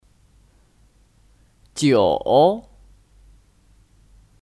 九 (Jiǔ 九)